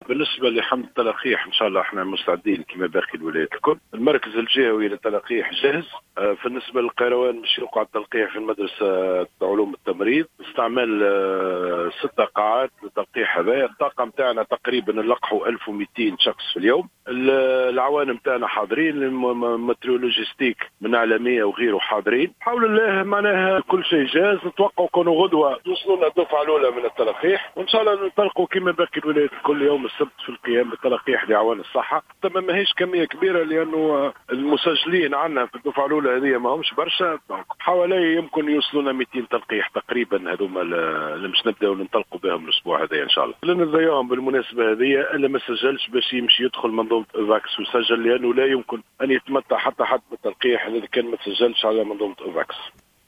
أكدّ المدير الجهوي للصحة بالقيروان محمد رويس في تصريح للجوهرة "اف ام"، اليوم الخميس، أن المركز الجهوي للتلاقيح أصبح جاهزا للإنطلاق يوم السبت القادم، في حملة التلقيح، التي ستشمل في مرحلة أولى أعوان الصحة.